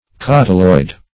cotyloid \cot"y*loid\, cotyloidal \cotyloidal\(k?t"?-loid), a.